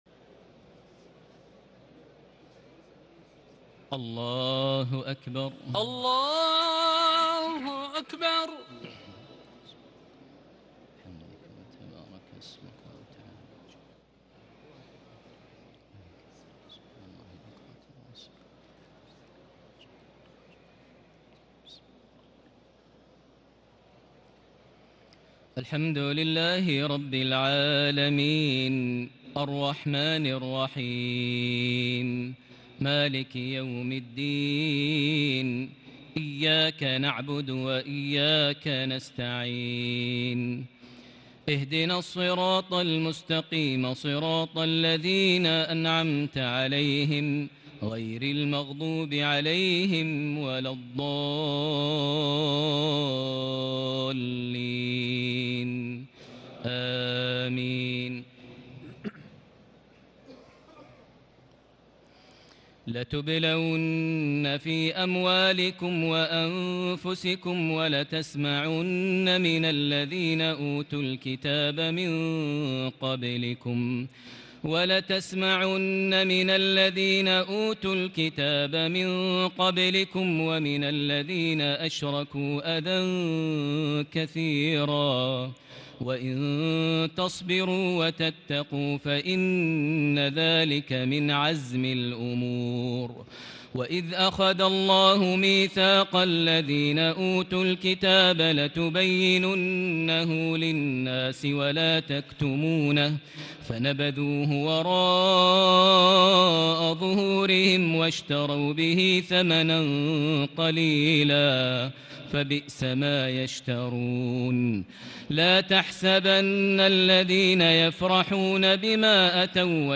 تهجد ليلة 24 رمضان 1440هـ من سورتي آل عمران (186-200) و النساء (1-24) Tahajjud 24 st night Ramadan 1440H from Surah Aal-i-Imraan and An-Nisaa > تراويح الحرم المكي عام 1440 🕋 > التراويح - تلاوات الحرمين